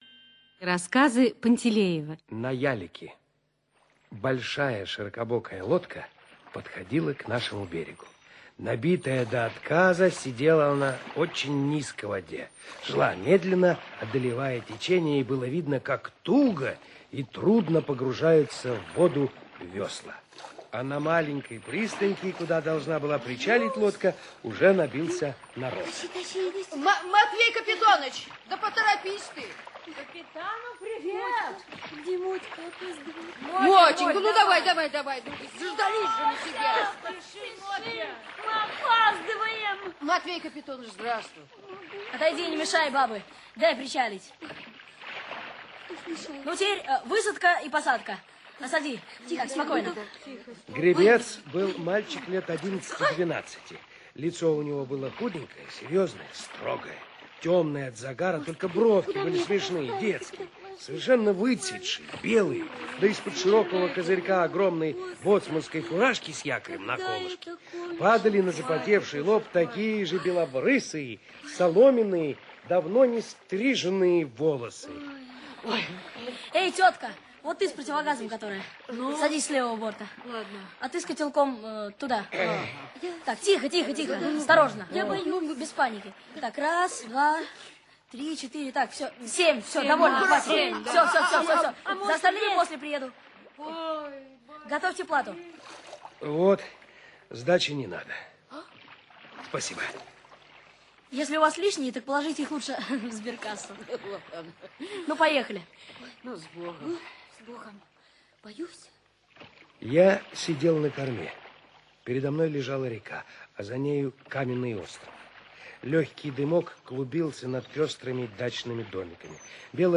На ялике - аудио рассказ Пантелеева Л. Рассказ про храброго одиннадцатилетнего мальчика, работавшего перевозчиком на ялике во время войны.